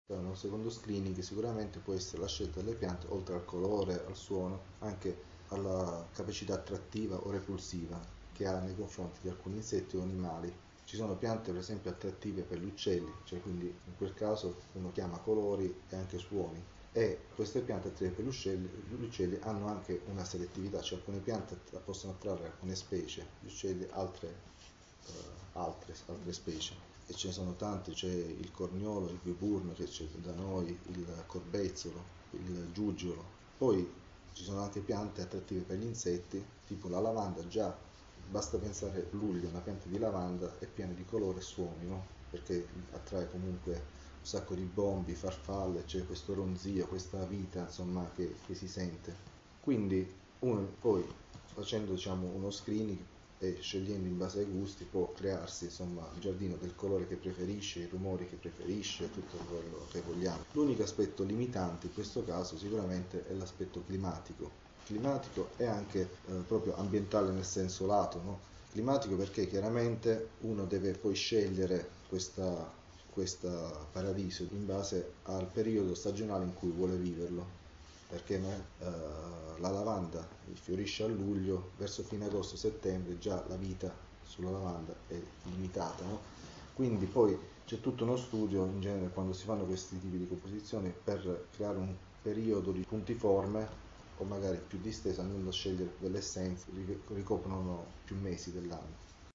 5-paesaggio-sonoro.mp3